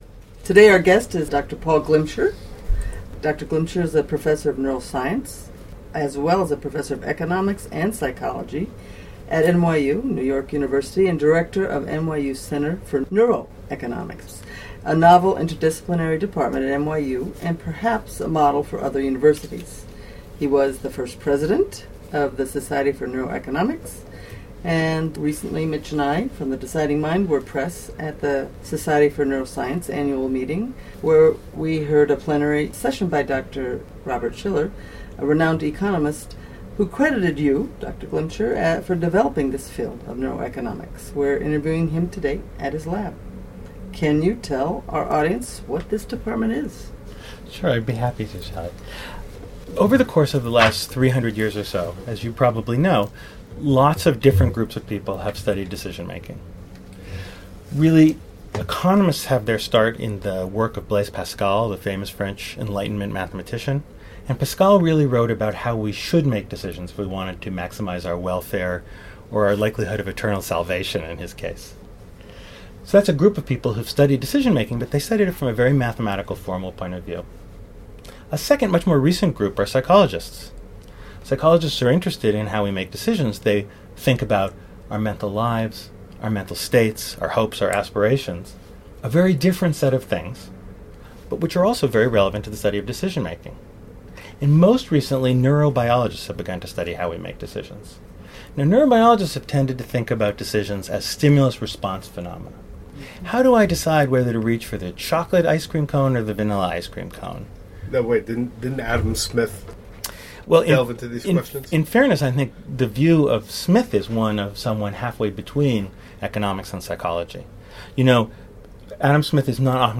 The Deciding Mind: Society for Neuroscience Interviews (Audio)